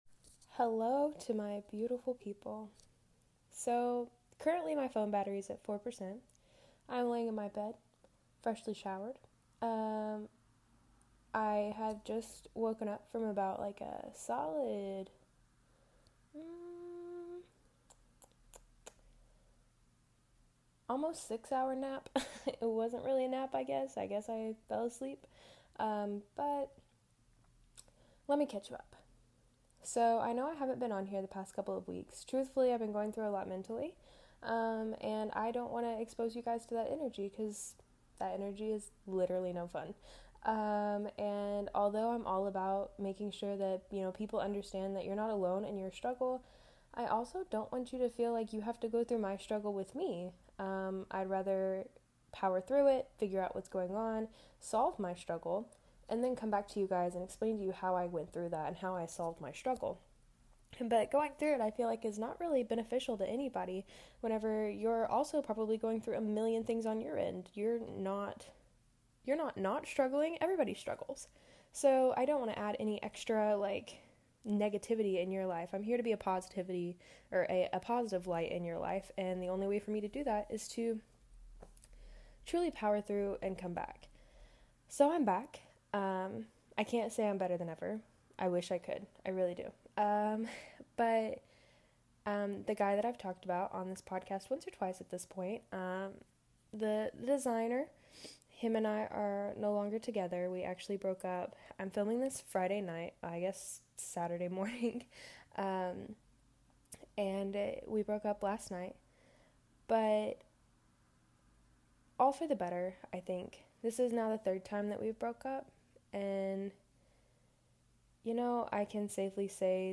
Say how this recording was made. Recorded after a stress nap that had to have been one of the best naps of my life; I talk about moving to Los Angeles, the trials of heartbreak, and how shitty it feels to sometimes have to do it all alone.